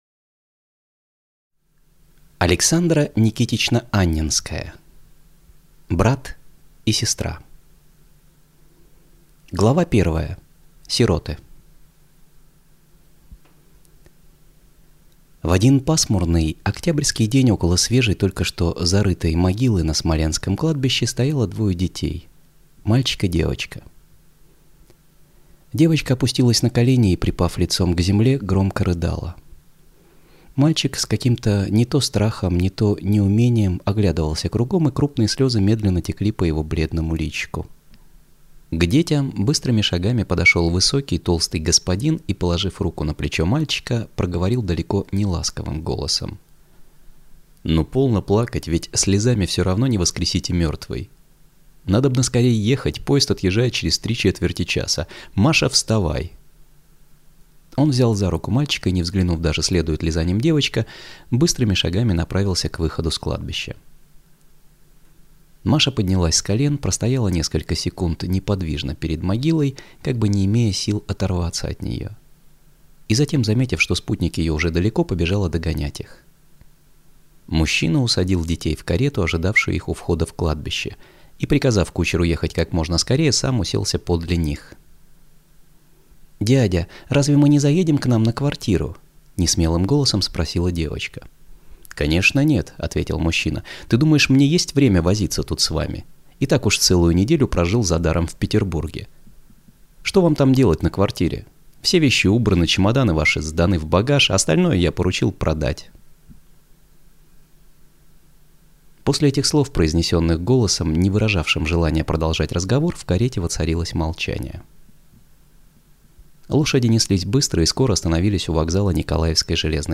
Аудиокнига Брат и сестра | Библиотека аудиокниг